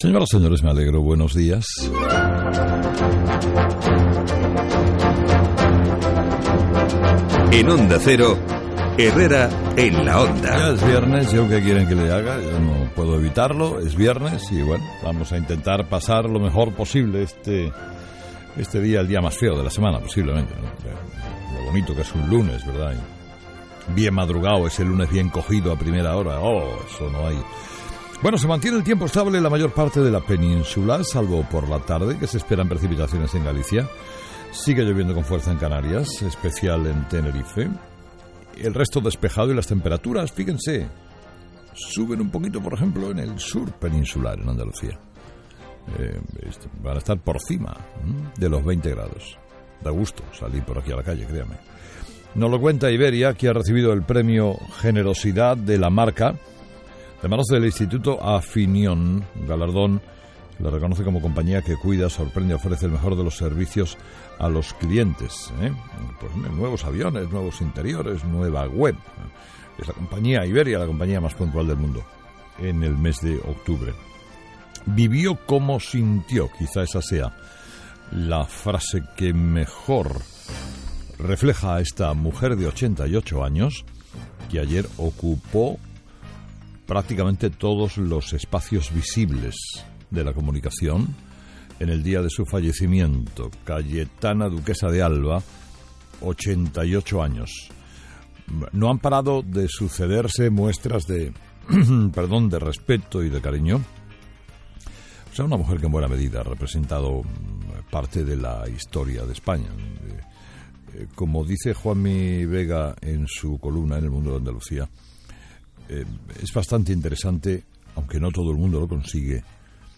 21/11/2014 Editorial de Carlos Herrera: Cayetana de Alba vivió como sintió